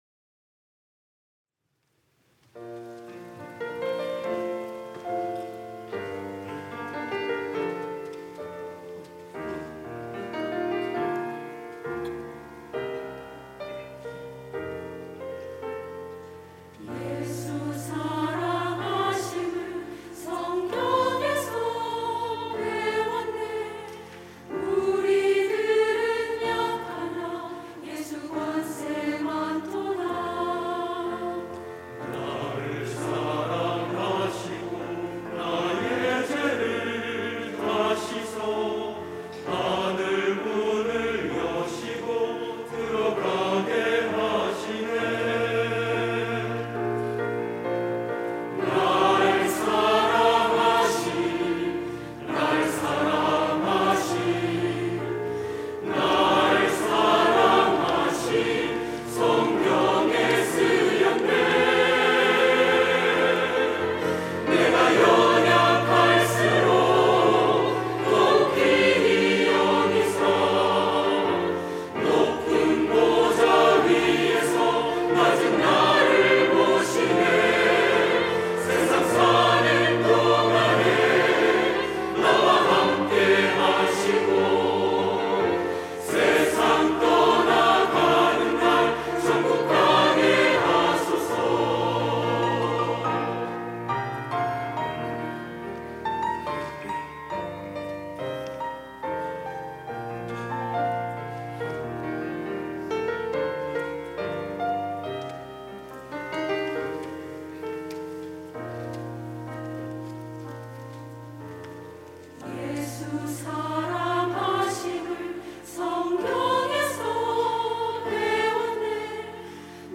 할렐루야(주일2부) - 예수 사랑하심을
찬양대